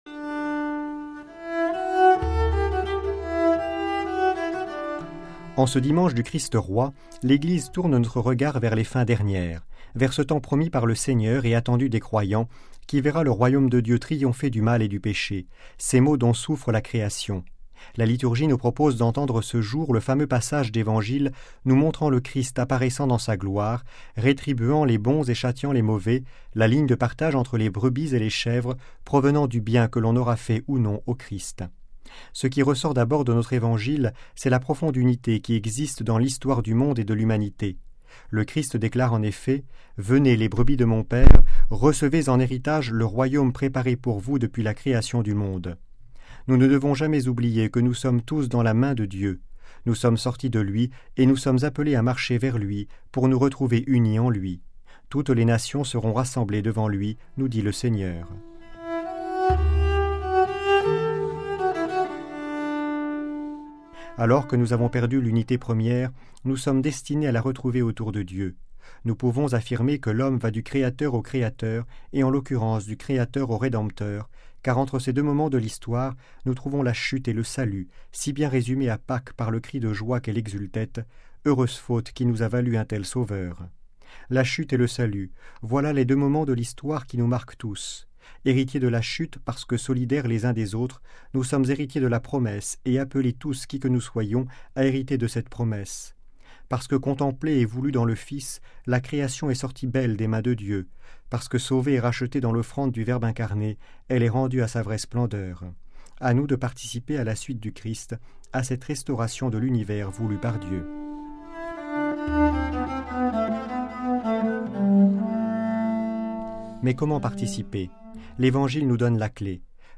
Le commentaire de l'Evangile : Christ roi de l'univers